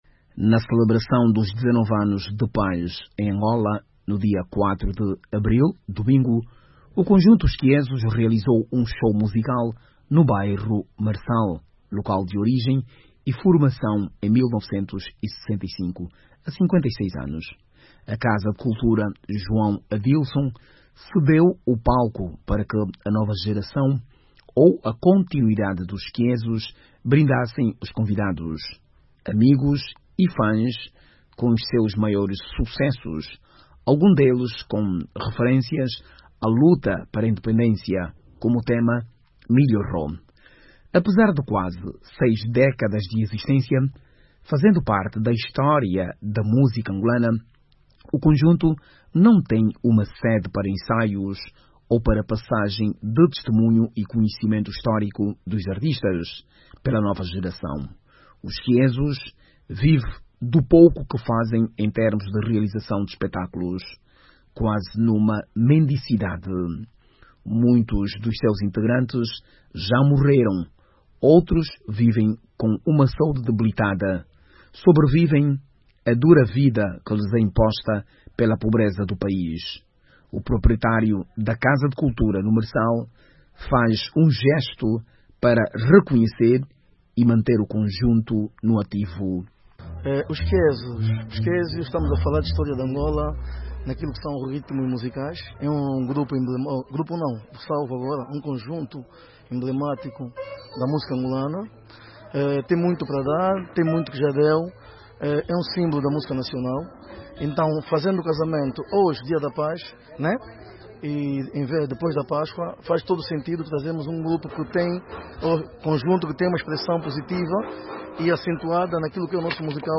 Para celebrar os dezanove anos de paz em Angola o conjunto Os Kiezos realizou um espectáculo musical no bairro Marçal, na casa de cultura João Adilson. Os melhores temas da carreira dos Kiezos voltaram a ser ouvidos no Dia da Paz e Reconciliação Nacional.